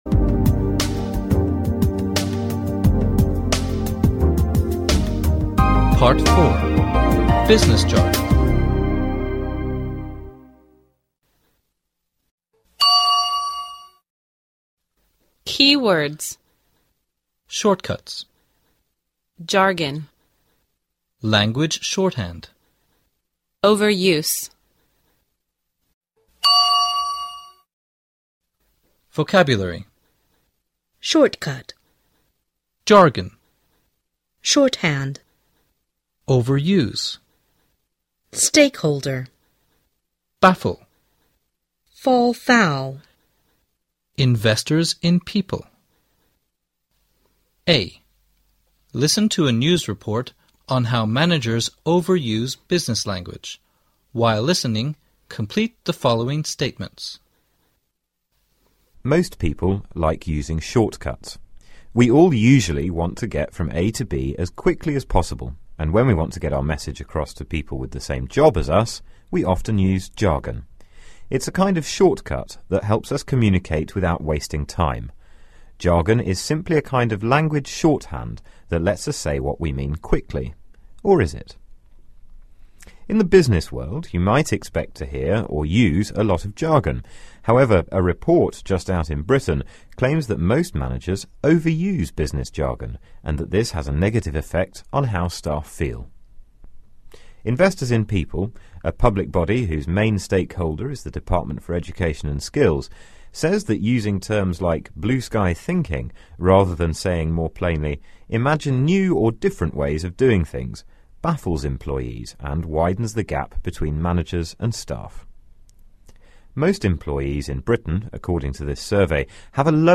A. Listen to a news report on how managers overuse business language.While listening, complete the following statements.